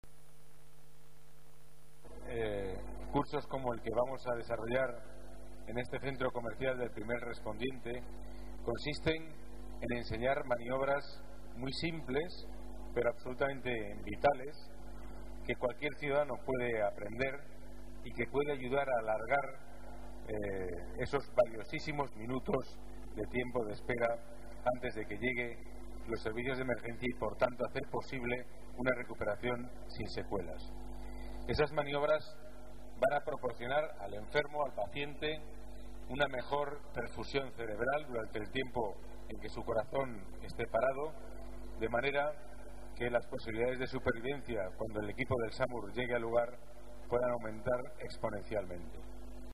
Nueva ventana:Declaraciones de Pedro Calvo, delegado de Seguridad